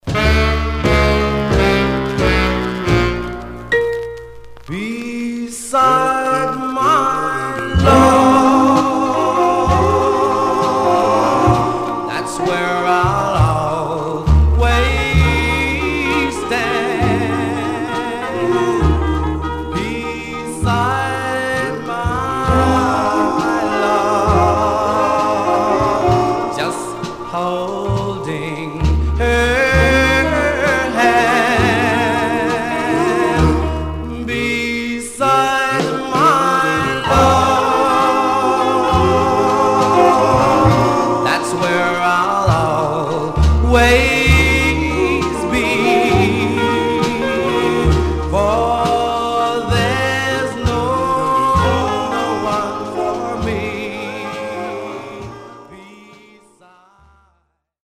Stereo/mono Mono
Male Black Groups